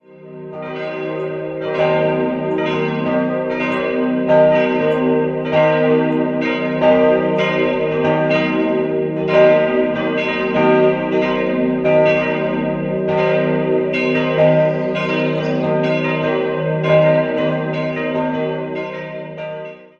4-stimmiges Geläute: es'-g'-b'-c'' Alle Glocken wurden im Jahr 1949 von Rudolf Perner gegossen.